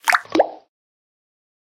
snd_splash1.ogg